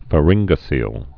(fə-rĭnggə-sēl)